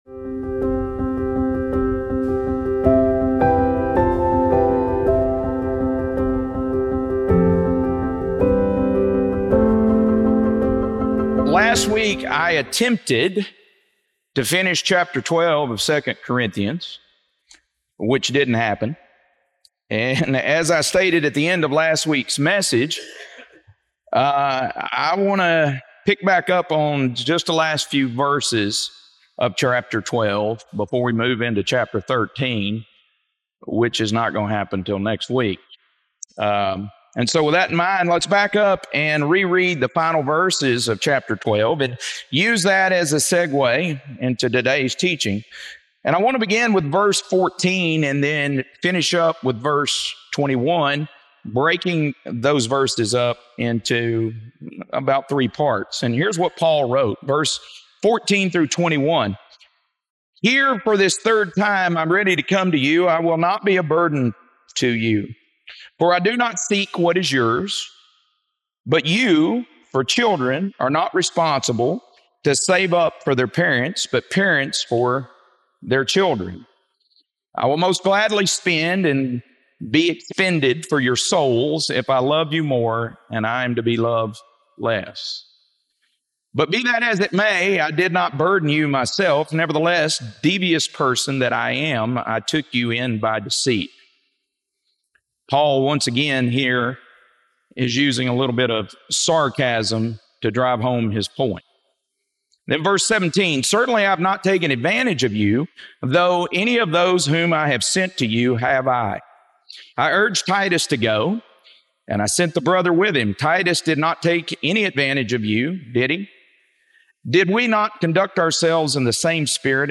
2 Corinthians - Lesson 12D | Verse By Verse Ministry International